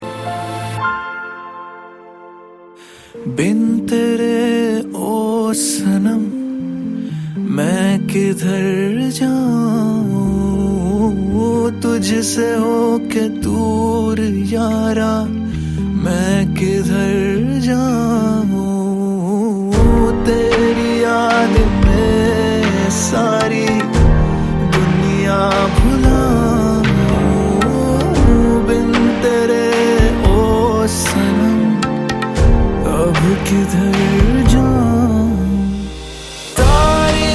Romance song of the year!